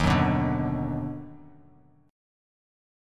Listen to D#mM7#5 strummed